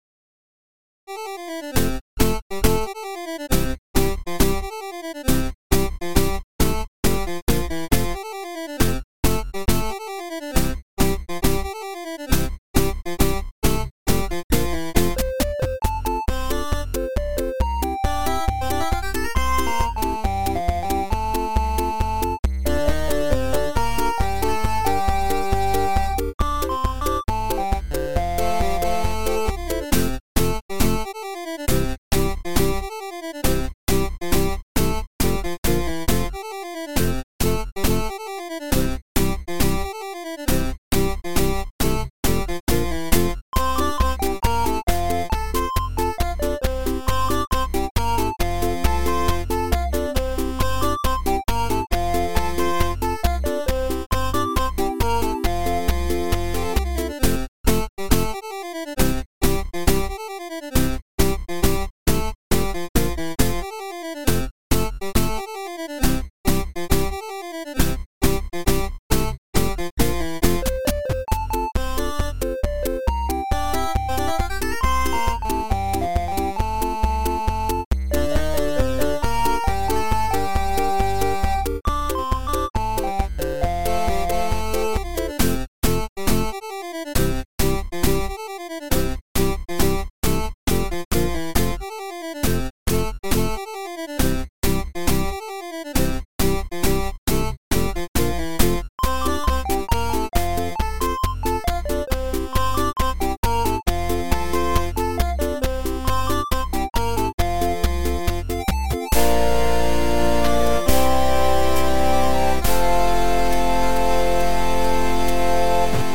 a versão gamer